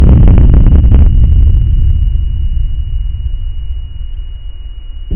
thumper hidden tone in slow speed playback
So here is the recording of the single thumper at a slower speed where the tone pop’s up to show itself.
thumper-hidden-tone-in-slow-speed-playback-web.mp3